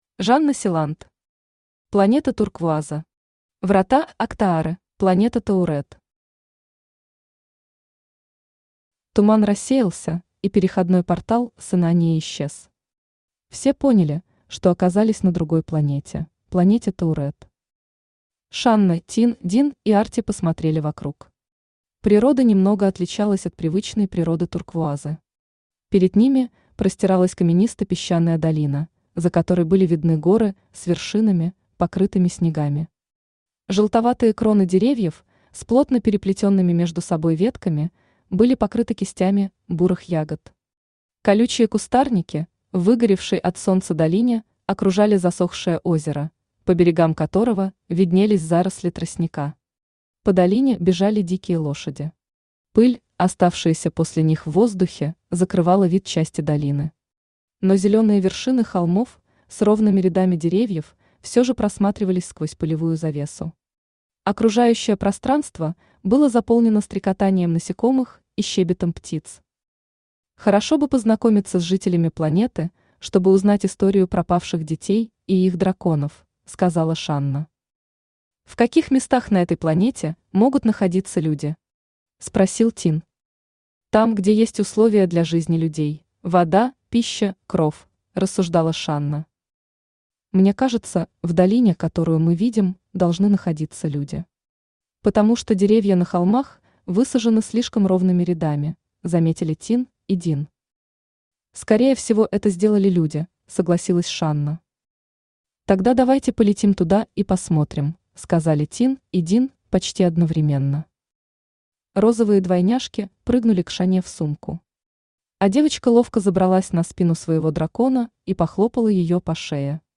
Аудиокнига Планета Турквуаза. Врата Октаары | Библиотека аудиокниг